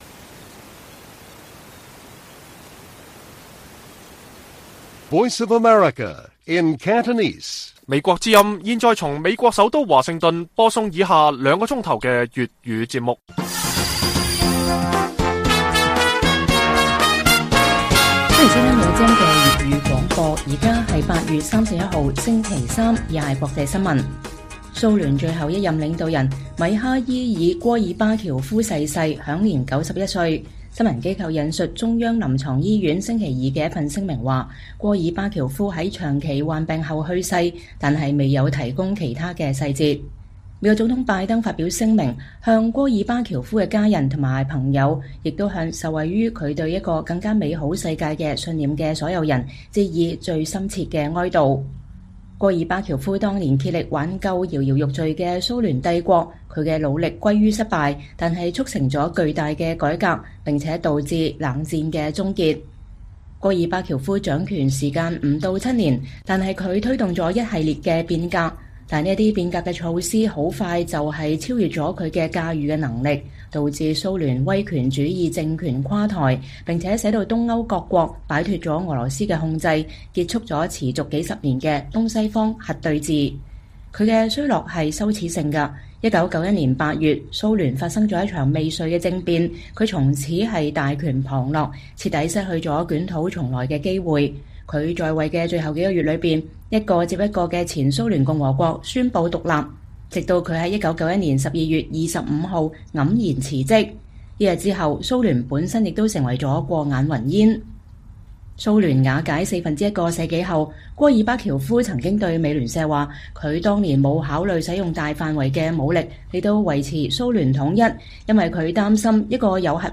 粵語新聞 晚上9-10點: 拜登總統對前蘇聯總統戈爾巴喬夫逝世致以“最深切哀悼”